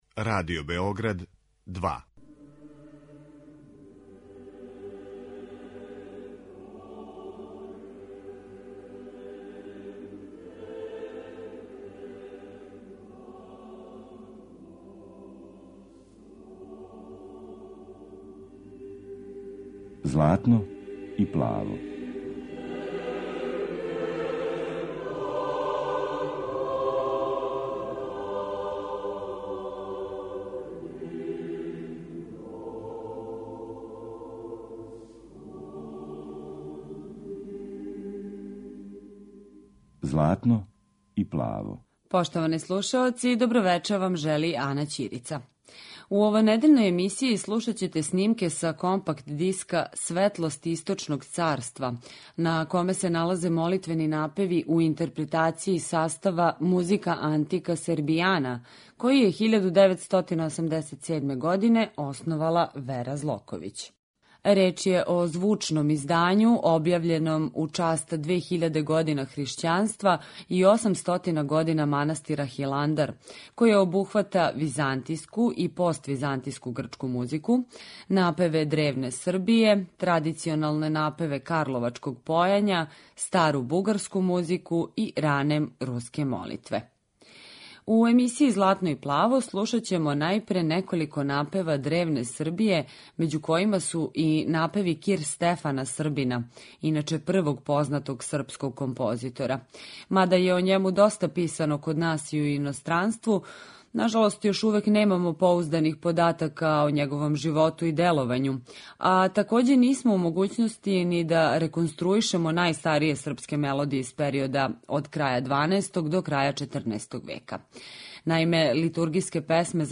Реч је о звучном издању објављеном у част 2000 година хришћанста и 800 година Хиландара, које обухвата византијску и поствизантијску грчку музику, напеве древне Србије, традиционалне напеве из Карловаца, стару бугарску музику и ране руске молитве.